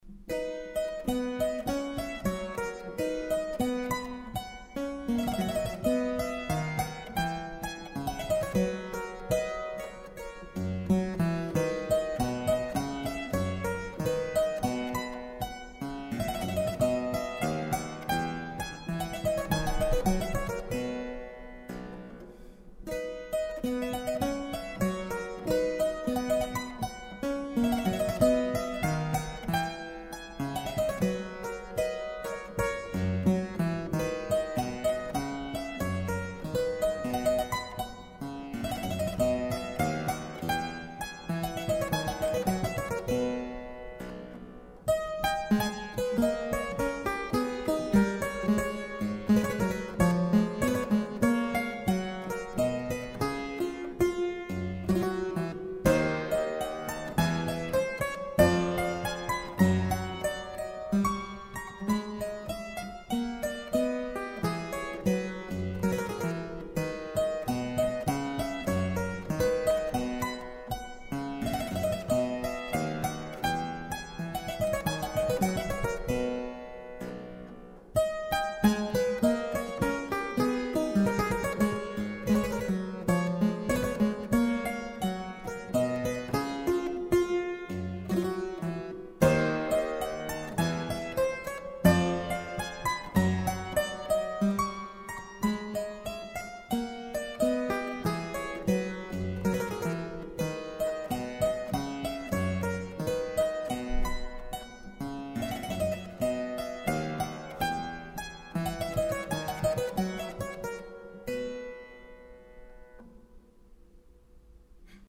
Andantino aus der Sonata c-moll von 1775